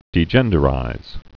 (dē-jĕndə-rīz)